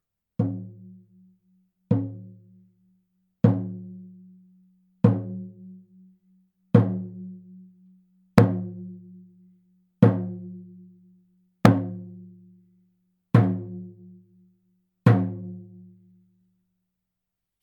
ネイティブ アメリカン（インディアン）ドラム NATIVE AMERICAN (INDIAN) DRUM 16インチ（buffalo バッファロー）
ネイティブアメリカン インディアン ドラムの音を聴く
乾いた張り気味の音です